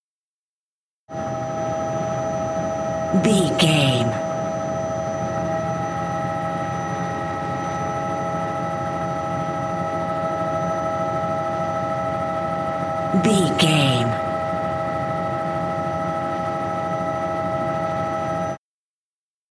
Helicopter fly int
Sound Effects
transportation